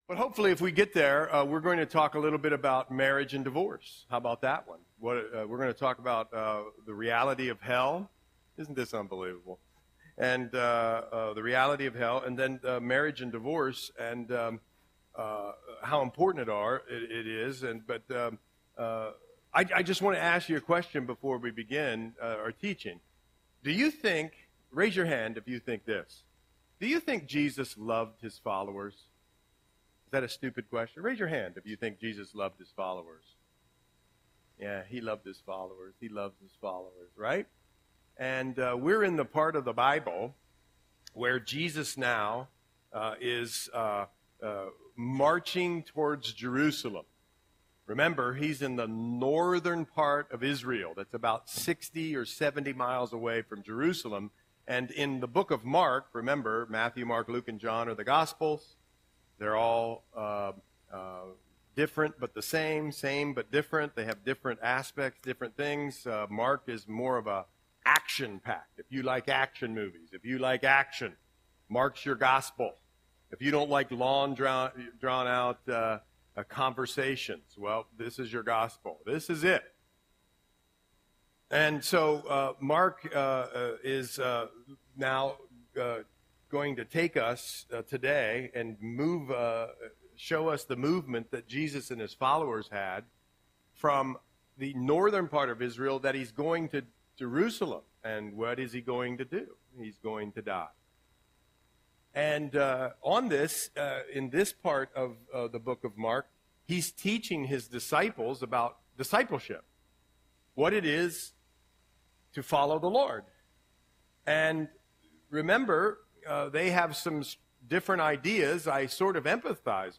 Audio Sermon - January 26, 2025